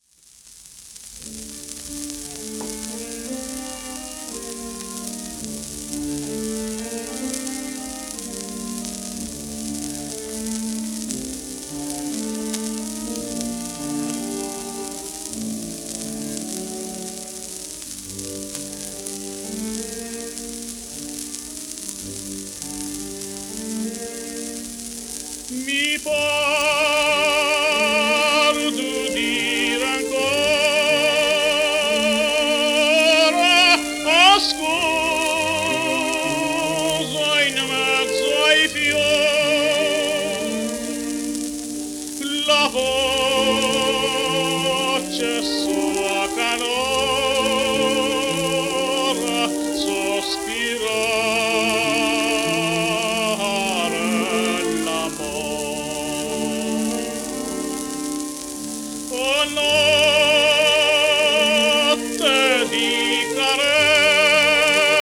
w/オーケストラ
戦前の欧州で人気のあったギリシャ出身のテナー。
シェルマン アートワークスのSPレコード